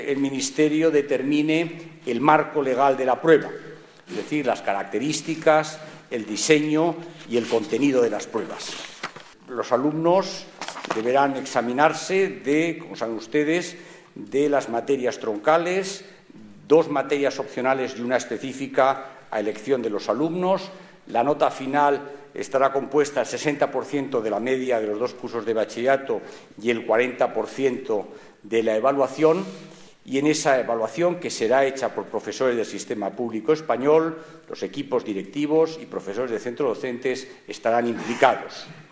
Declaraciones del ministro 1 Audio